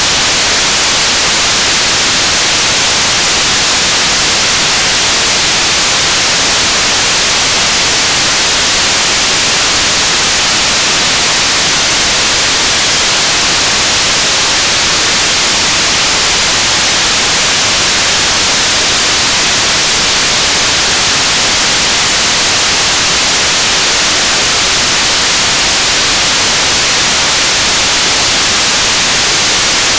Образцы сигналов ASTRO
Ниже представлен образец звучания в NFM сигнала Motorola ASTRO 25 HPD Air Interface:
apco_astro.ogg